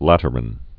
(lătər-ən)